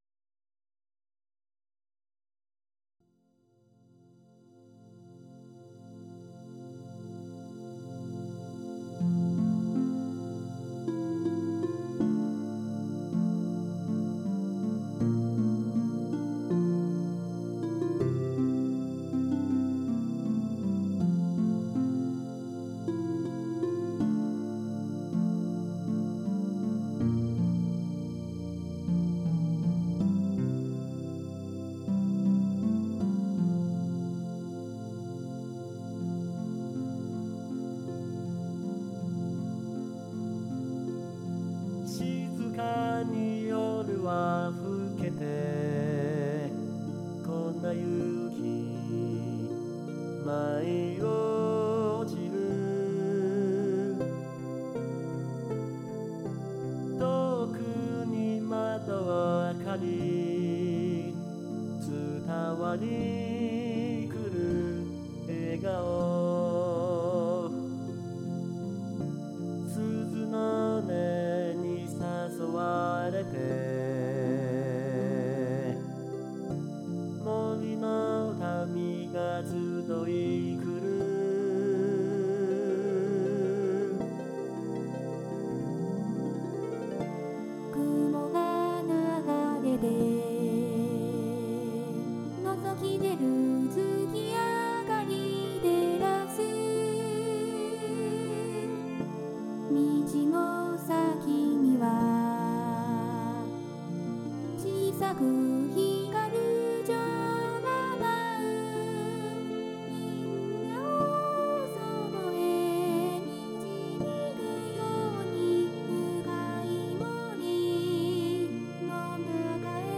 オリジナル曲
クリスマスソング